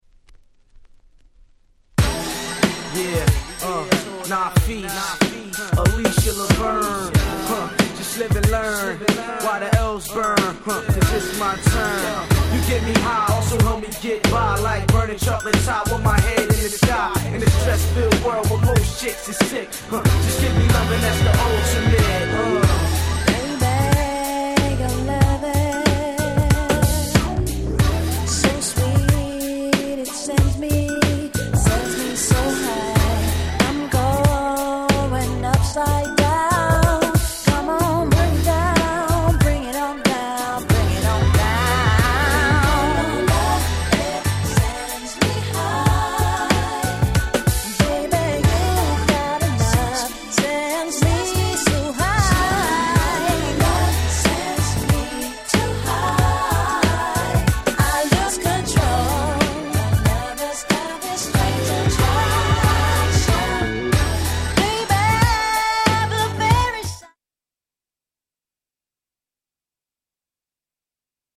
も素敵なMid UK R&Bに仕上がっております♪